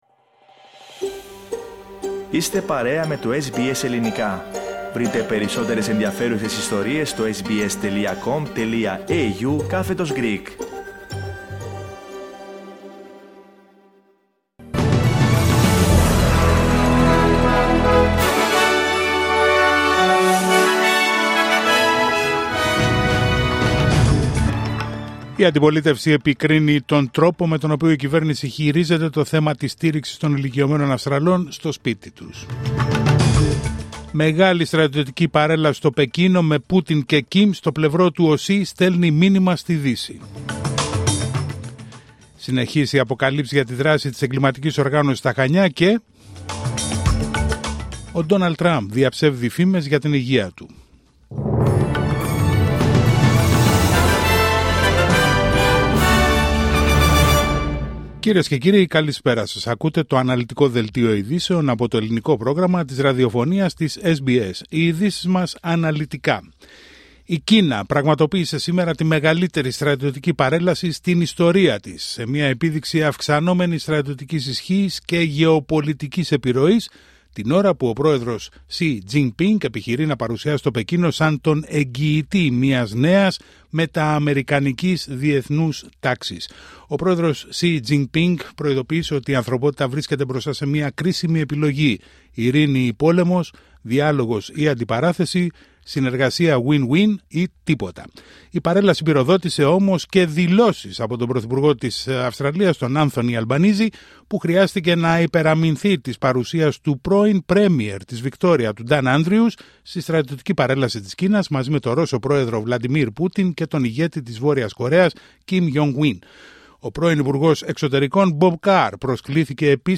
Δελτίο ειδήσεων Τετάρτη 3 Σεπτεμβρίου 2025